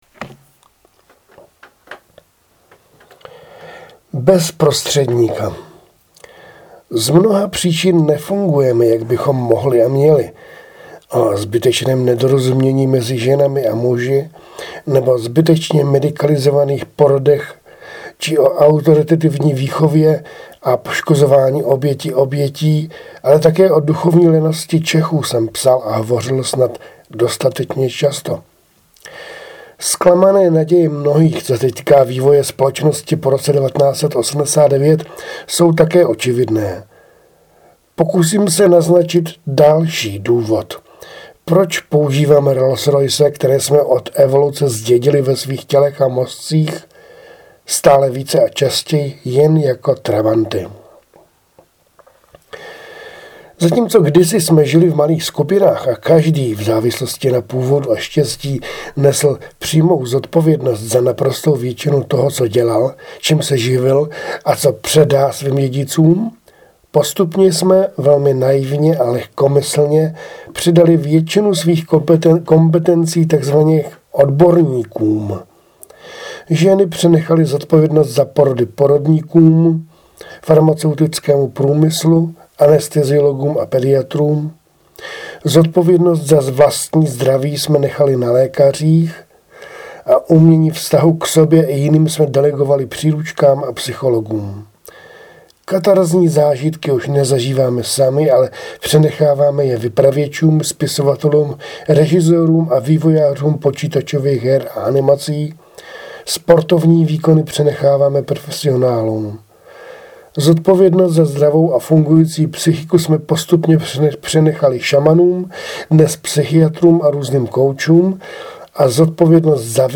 Autorsky namluvený fejeton